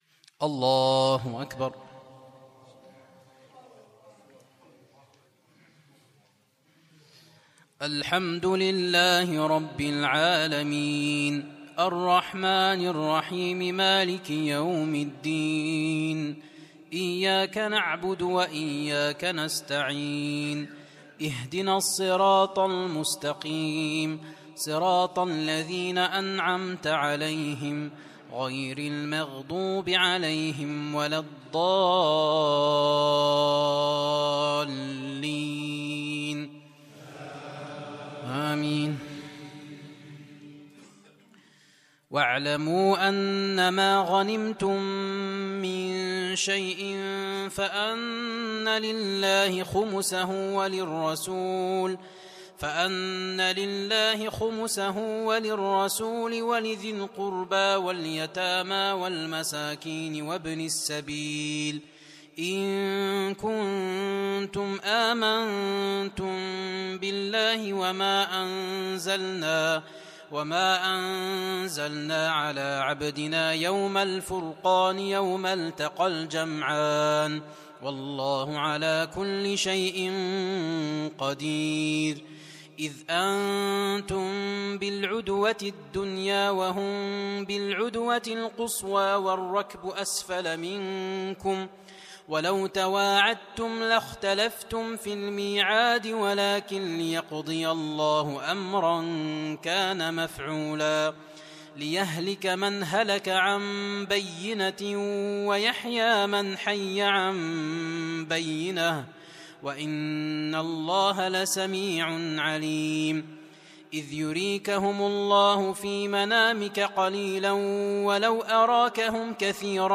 Tarawih 2016-1437